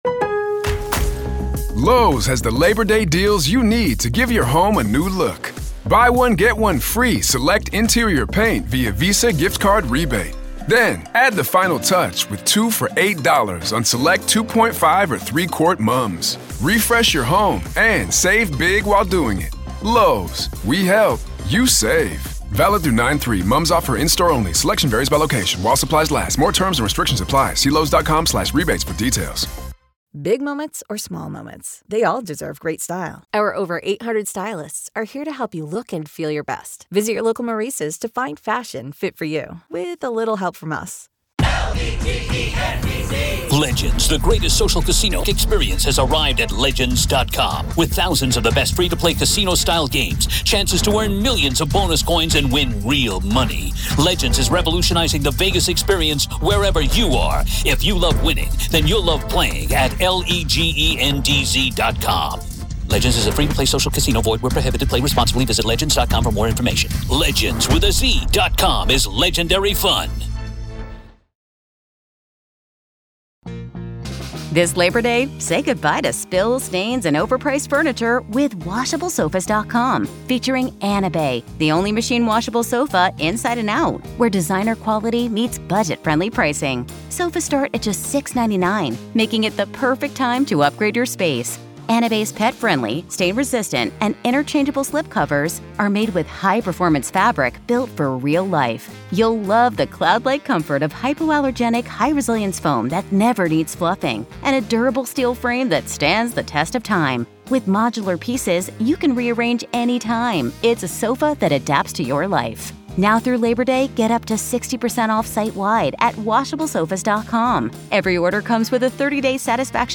Tune in for an enlightening dialogue about the impact of gag orders on public discourse and perception.